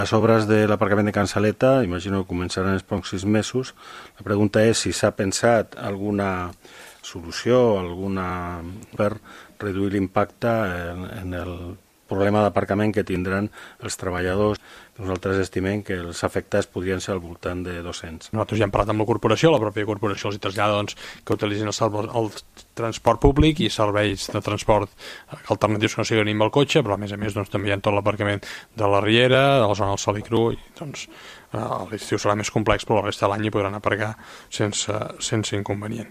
Va ser en el ple ordinari de dijous passat.